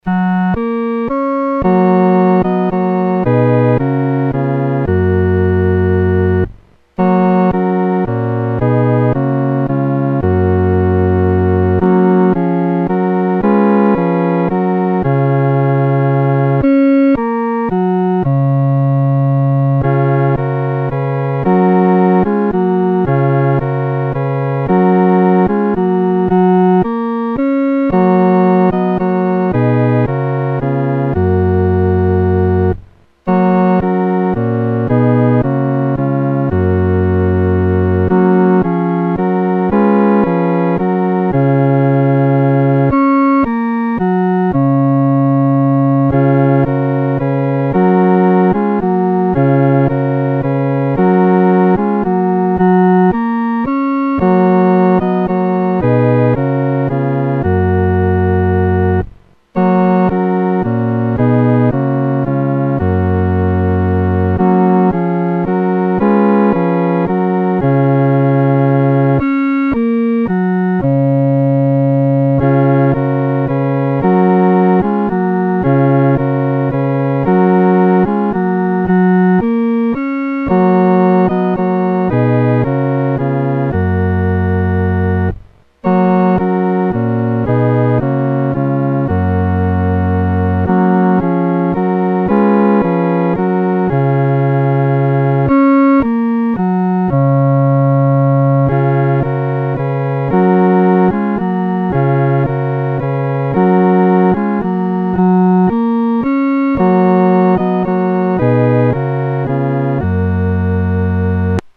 独奏（第四声）
恳求三一来临-独奏（第四声）.mp3